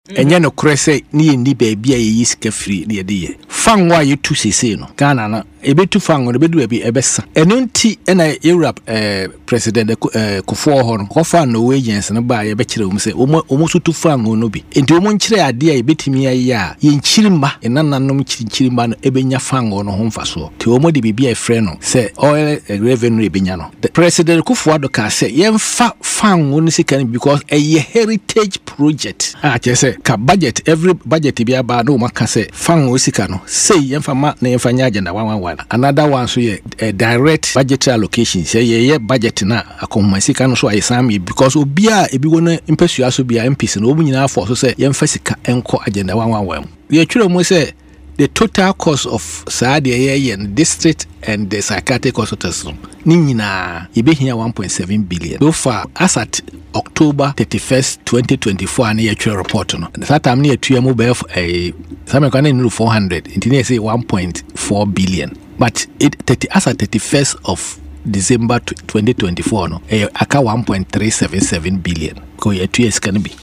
Speaking on Adom FM’s Burning Issues, Dr. Nsiah Asare dismissed these claims, emphasizing that the project does not require new funding, as previous budget allocations had been made under the former administration.